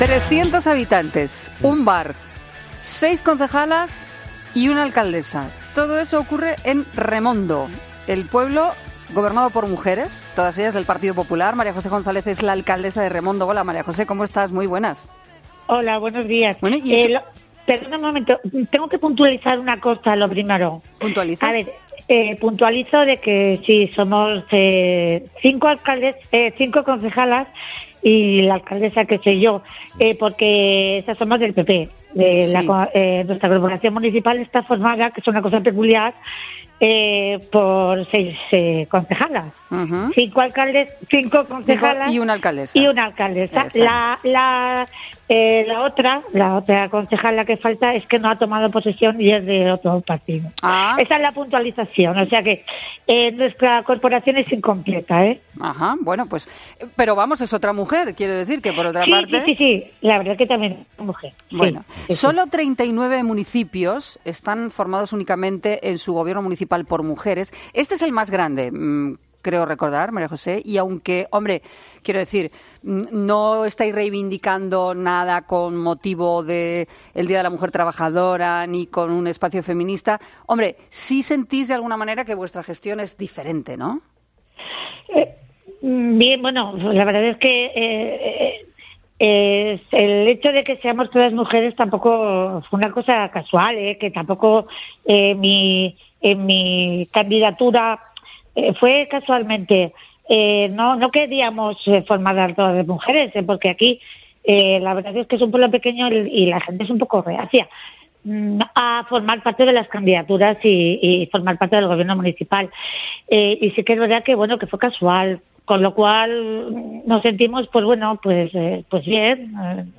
Entrevista a la alcaldesa de Remondo, María José González